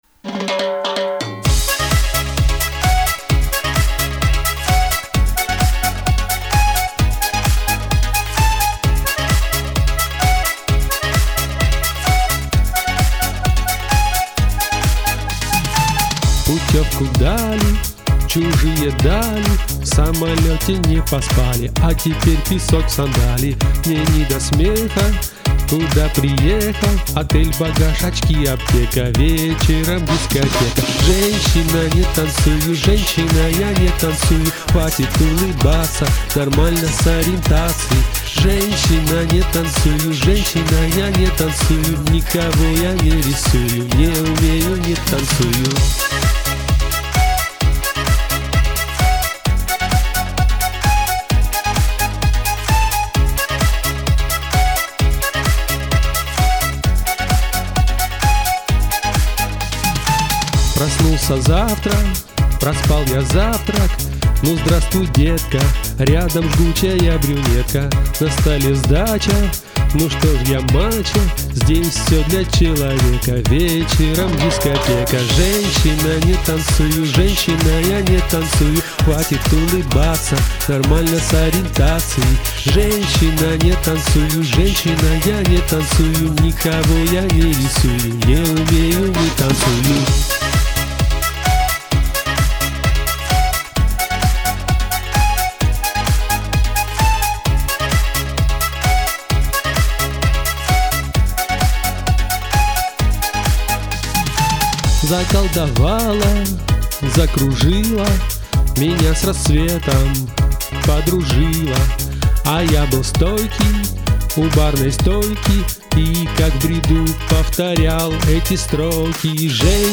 А голос у вас приятный и спели с настроением! dada